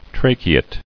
[tra·che·ate]